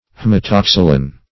Haematoxylon \H[ae]m`a*tox"y*lon\ (-l[o^]n), n. [NL., fr. Gr.